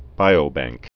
(bīō-băngk)